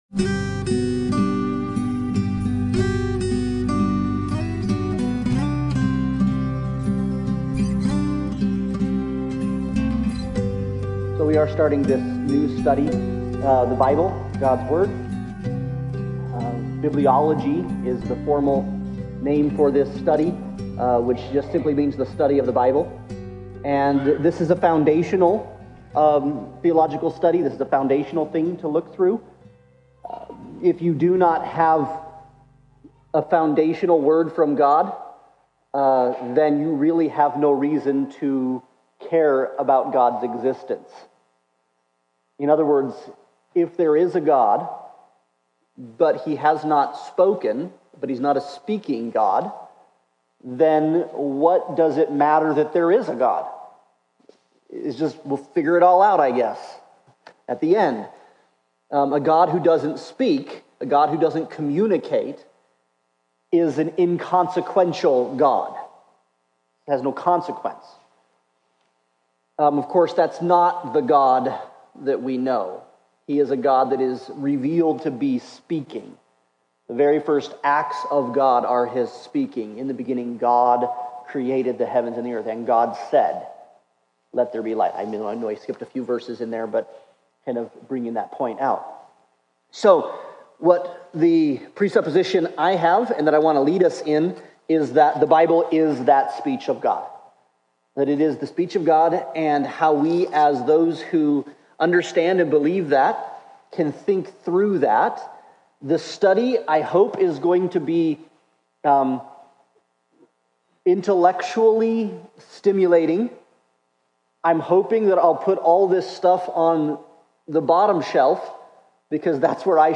God's Word Service Type: Sunday Bible Study « To the Elect Exiles